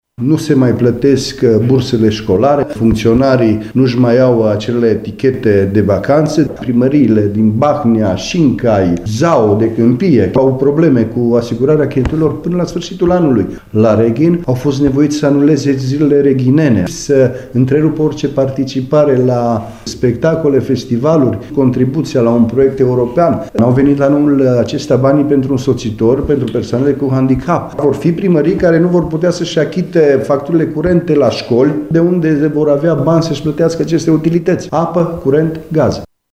Chirteș a subliniat că nu creșterea salariilor a avut acest efect, ci modificarea de către Guvernul Dăncilă a Codului Fiscal: